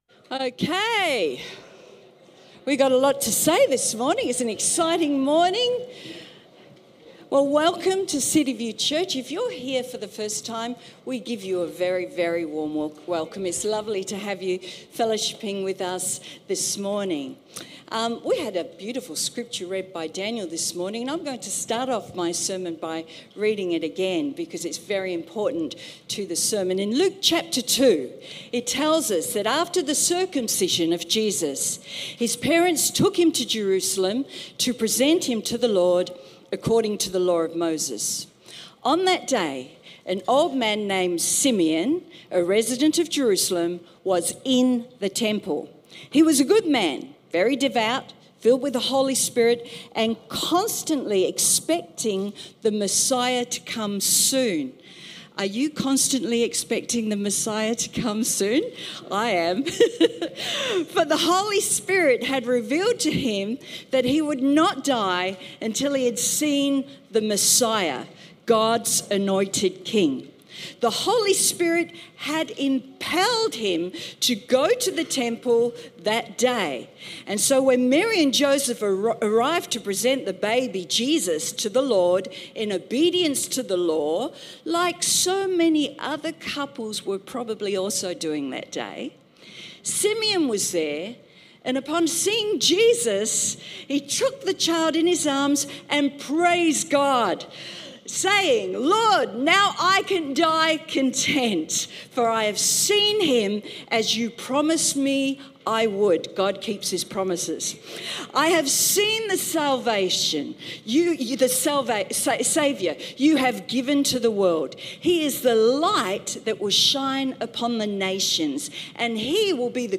Sermon Transcript In Luke chapter 2 it tells us that, after the circumcision of Jesus, His parents took Him to Jerusalem to present Him to the Lord according to the Law of Moses.